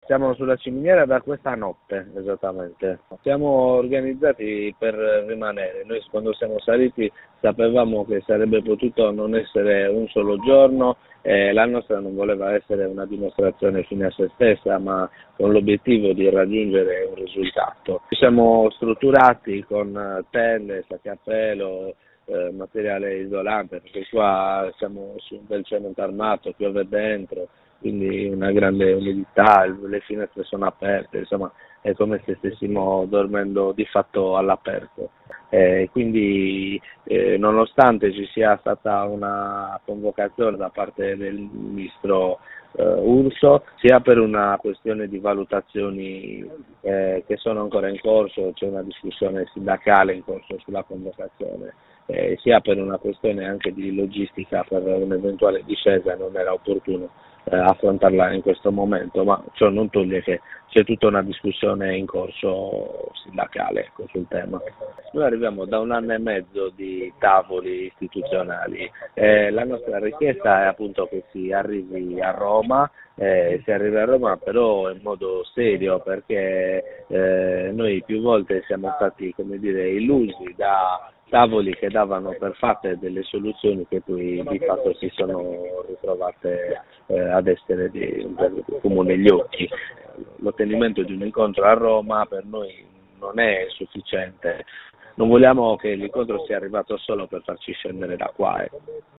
L’apertura del governo non è bastata per far scendere i lavoratori, li abbiamo raggiunti al telefono, ecco cosa ci hanno raccontato